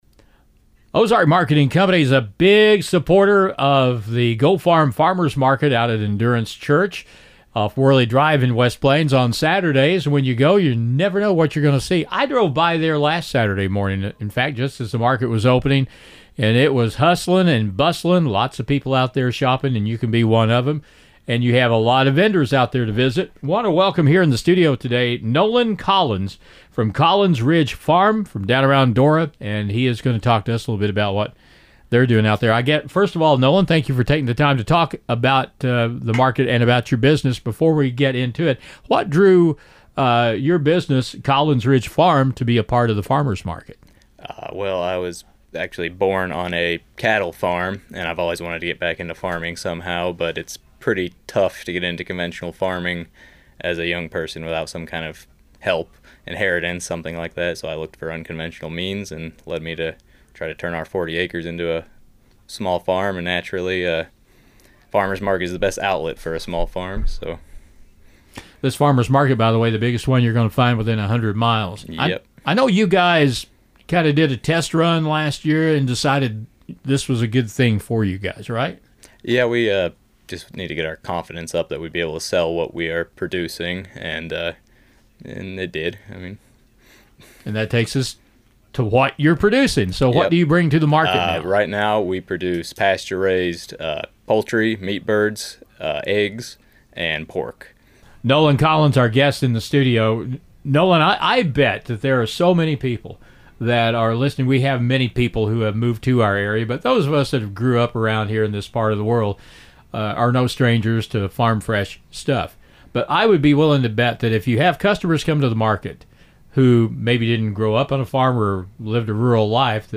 GO-Farm-Interview-4-22-25.mp3